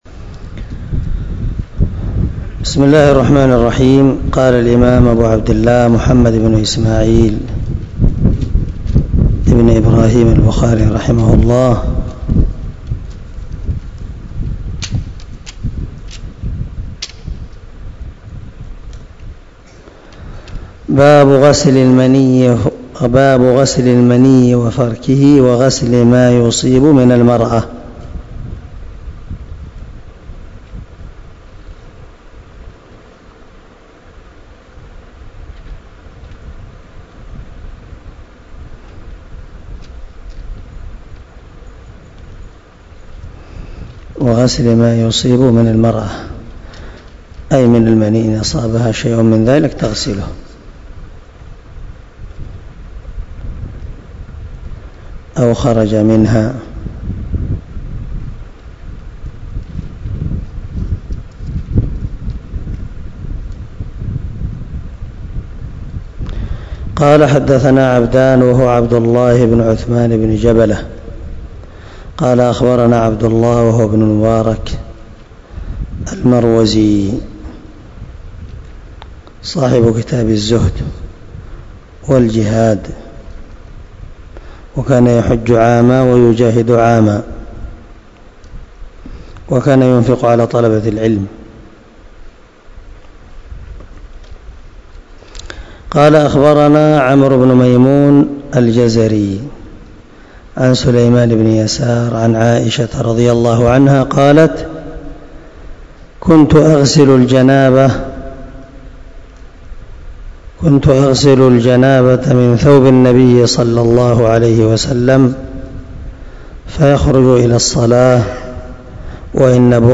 193الدرس 69 من شرح كتاب الوضوء حديث رقم ( 229 – 230 ) من صحيح البخاري